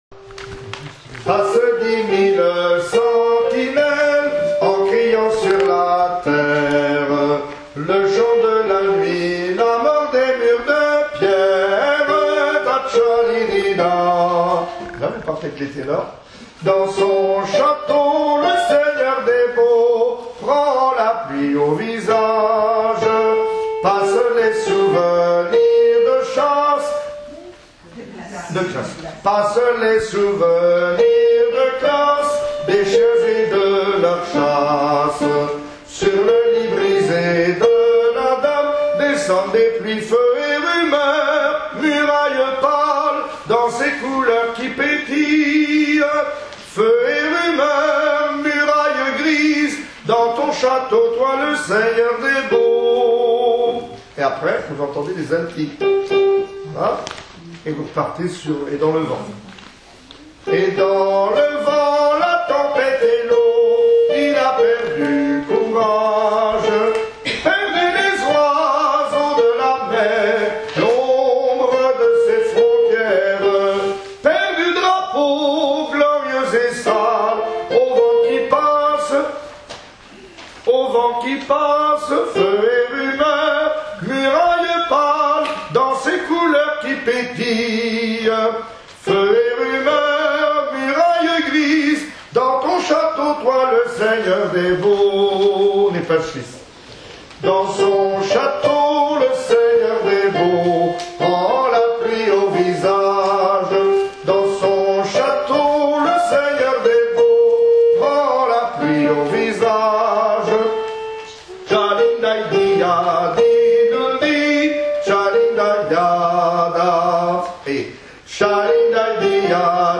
soprane